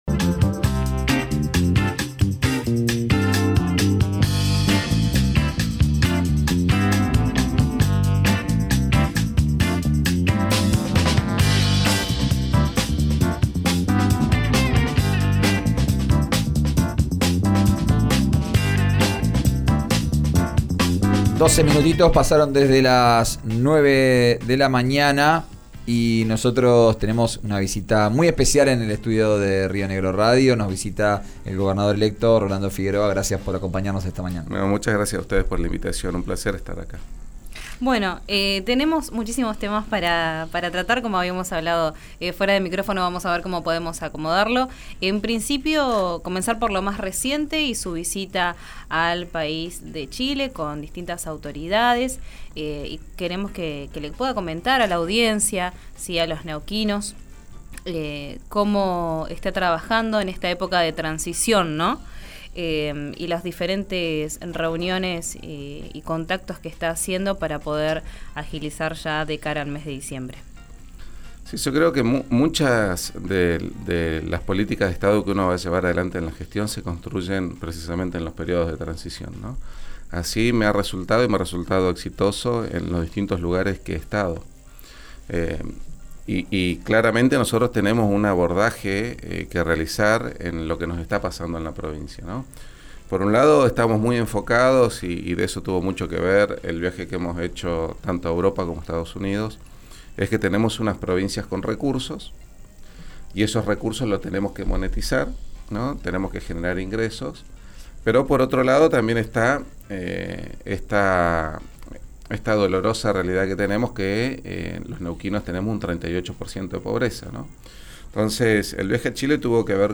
El gobernador electo consideró que hay que dejarla actuar para que le muestre a la sociedad quiénes fueron los responsables. En diálogo con RN Radio, habló sobre el futuro gabinete y su posición en las próximas PASO.